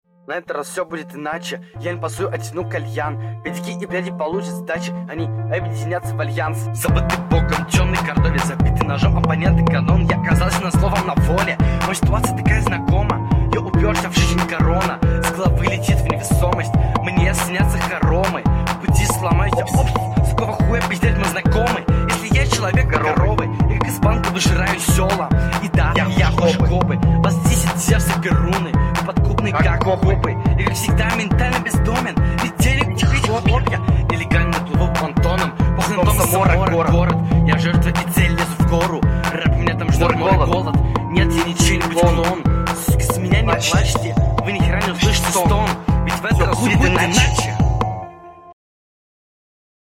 С дикцией очень большие проблемы.
Голос чересчур напряжённый, дикция хромает, громкость звука не сбалансирована